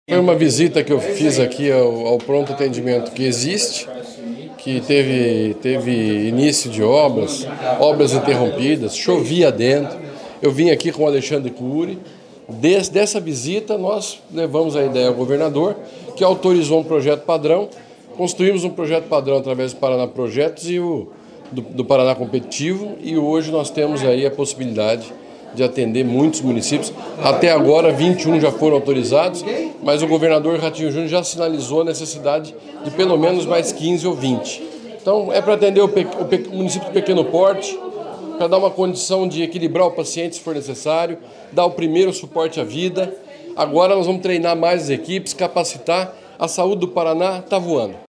Sonora do secretário da Saúde, Beto Preto, sobre a inauguração da primeira Unidade Mista de Saúde do Paraná em Maria Helena